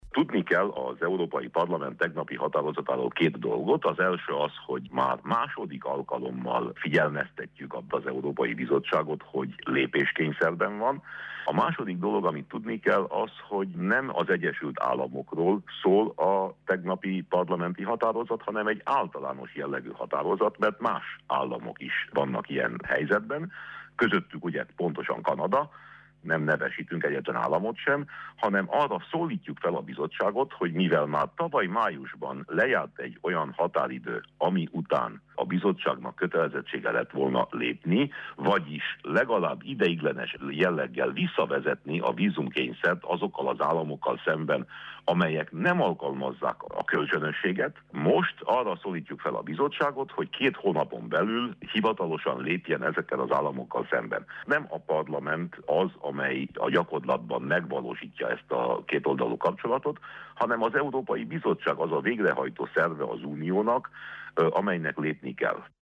Nemcsak az Amerikai Egyesült Államokkal szemben sürgette a vízumkényszer átmeneti jellegű visszaállítását tegnap az Európai Parlament. Winkler Gyula europarlamenti képviselő nyilatkozik: